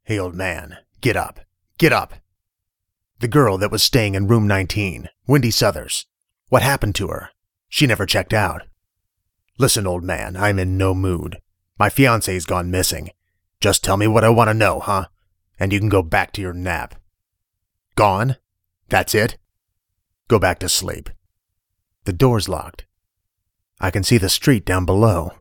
Voice 2
I like voice 2, reason, it's much clearer then voice 1 and eaiser to hear.
The second one is clearer but after hearing the emotion of voice 1 - it seems flat.
Voice 2 I heard youth, and street type, anger without back up.